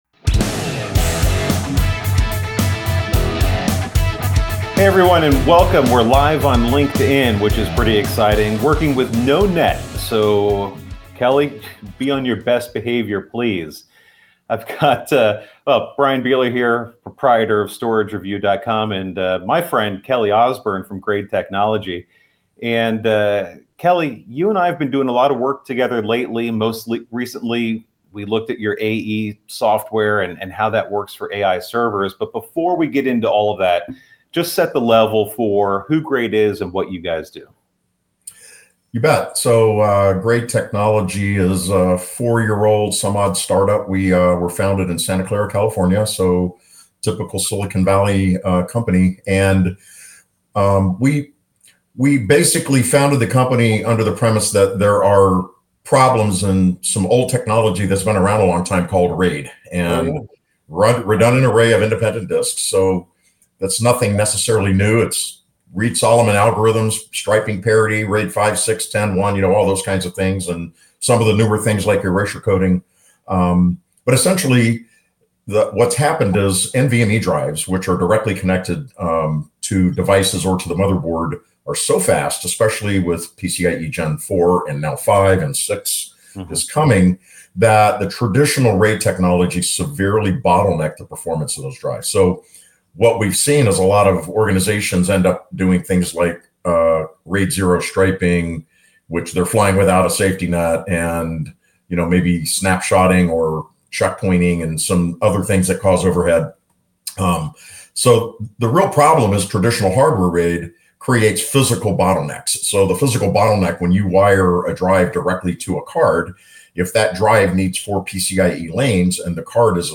Gain insight into improving RAID performance for AI workloads with Graid SupremeRAID AE in this LinkedIn Live replay.